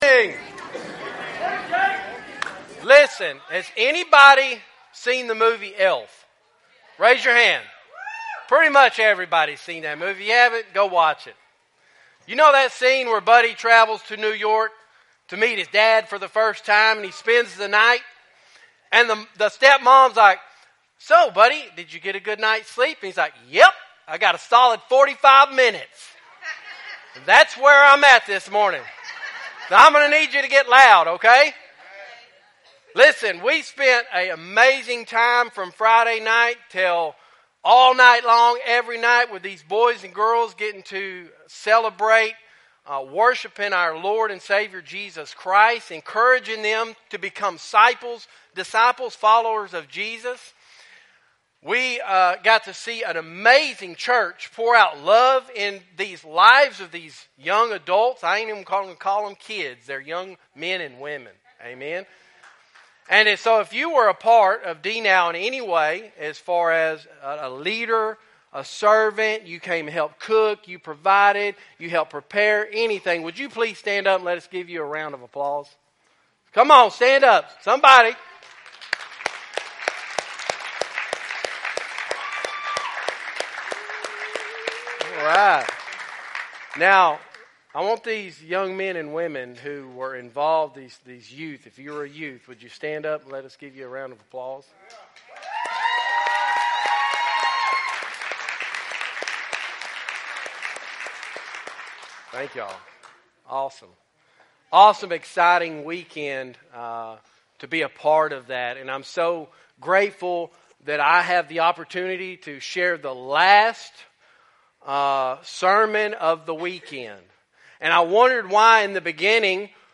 Cedar Creek Missionary Baptist Church Sermons